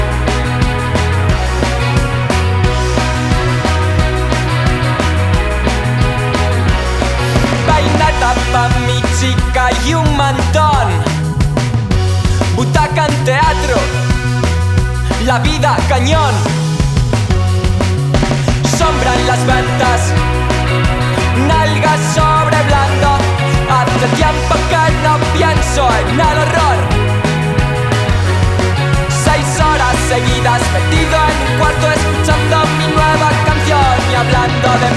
Жанр: Иностранный рок / Рок / Инди / Альтернатива